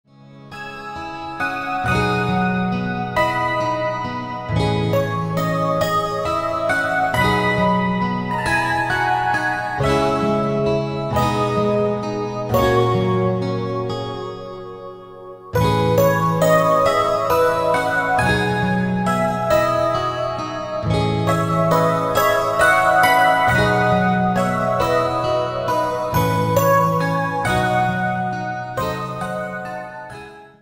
• Качество: 192, Stereo
красивые
грустные
спокойные
без слов
OST
Мелодичная музыка